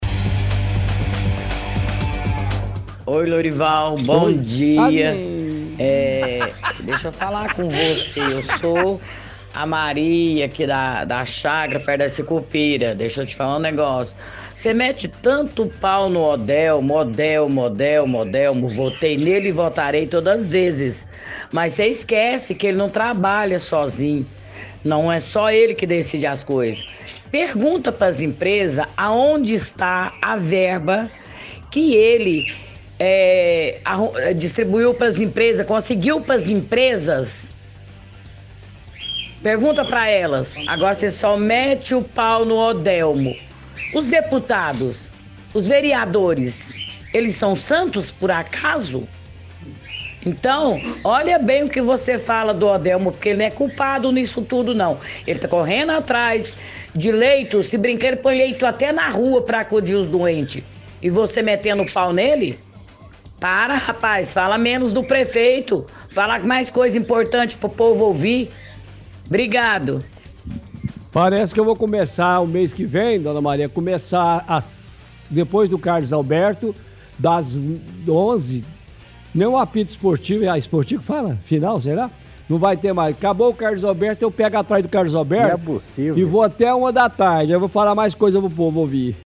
áudio antigo de ouvinte defendendo o prefeito Odelmo Leão.